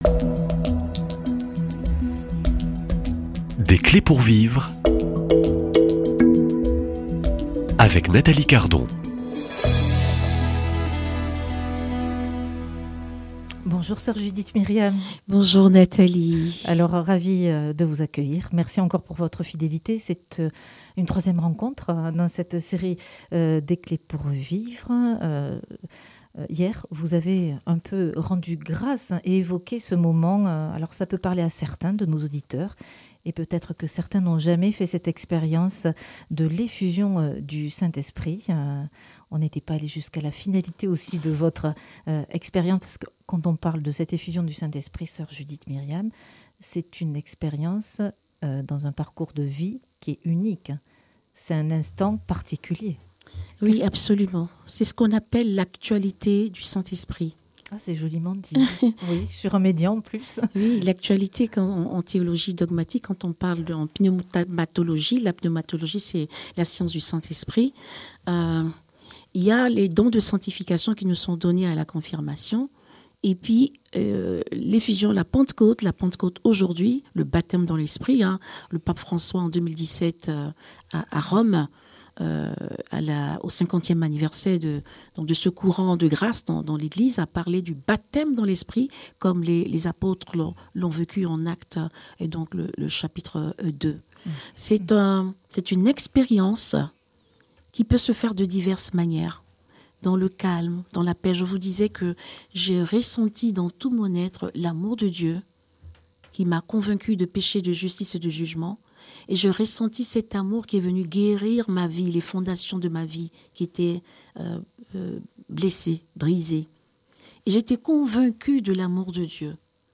Et si la vraie force de guérison venait d'un souffle intérieur ? Dans cet entretien de la série Des clés pour vivre,